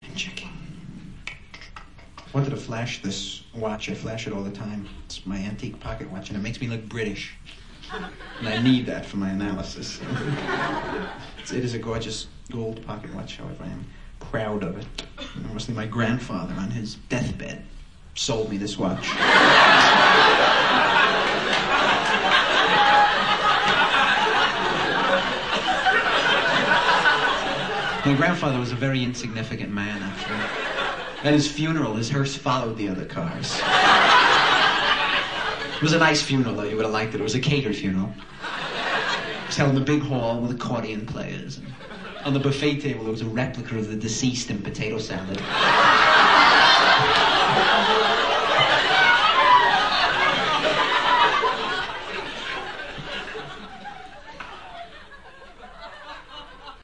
伍迪单口相声精选 第17期:我的爷爷My Grandfather 听力文件下载—在线英语听力室